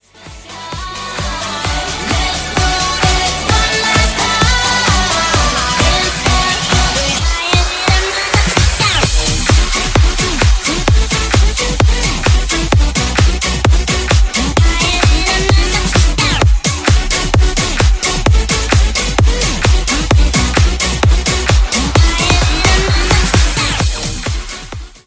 Genres: EDM , RE-DRUM , TOP40 Version: Dirty BPM: 130 Time